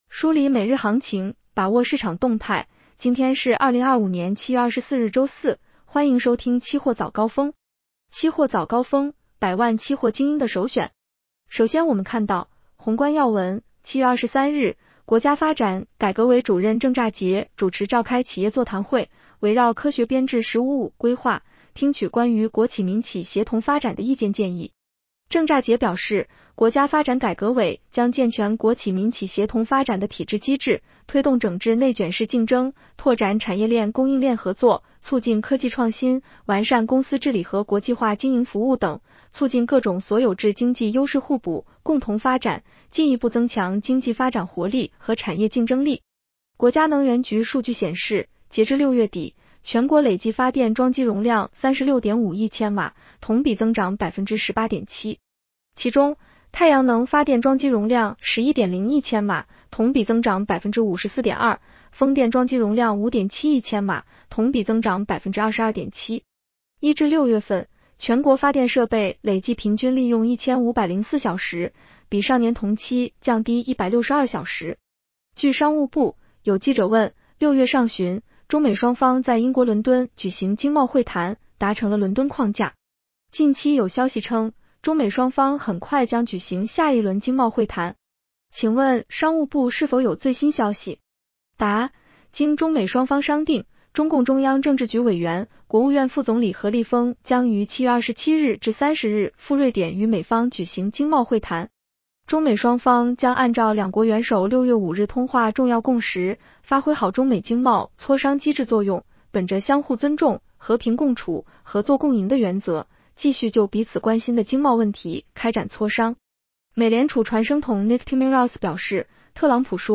期货早高峰-音频版
期货早高峰-音频版 女声普通话版 下载mp3 宏观要闻 1. 7月23日，国家发展改革委主任郑栅洁主持召开企业座谈会，围绕科学编制“十五五”规划，听取关于国企民企协同发展的意见建议。